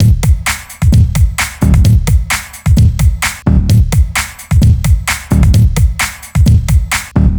TSNRG2 Breakbeat 003.wav